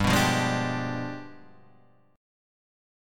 G 7th Flat 9th